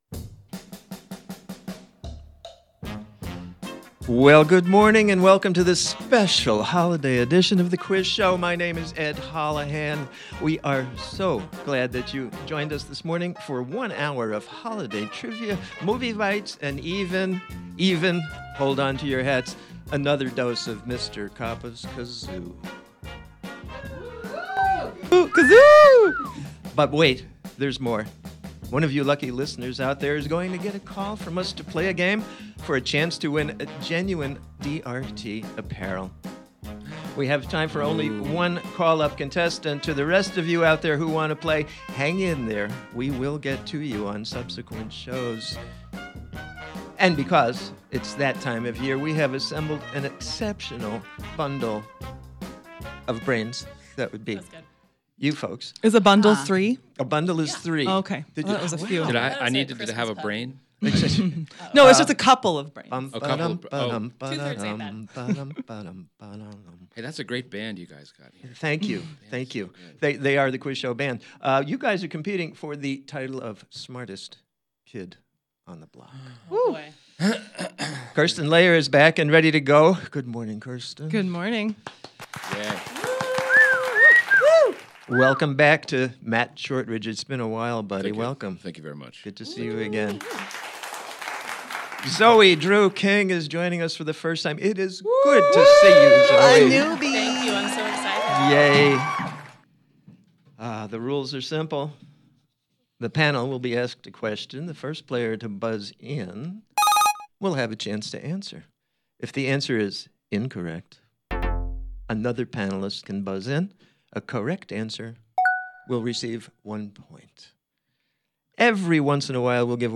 Christmas Quiz Show!